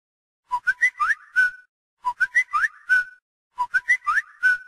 alarmanuevoturno.mp3